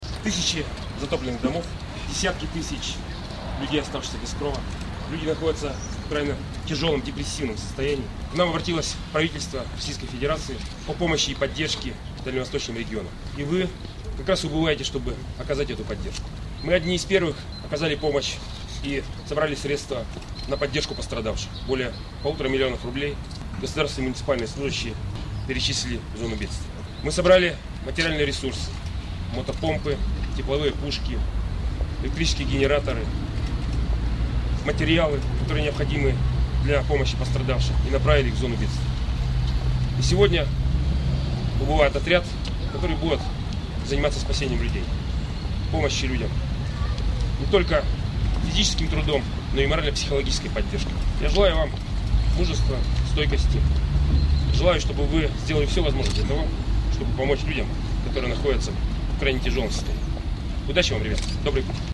Перед отправкой вологжан напутствовал губернатор Олег Кувшинников